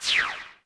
snd_arrow.wav